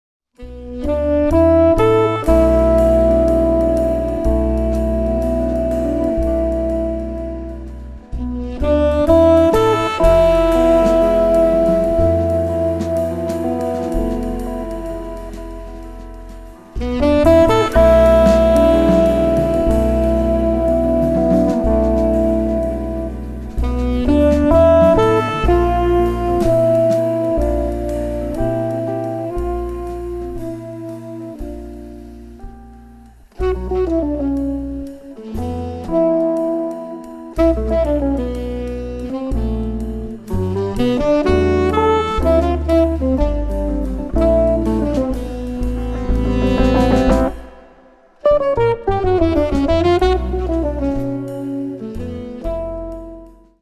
tenor sax
guitar
bass
drums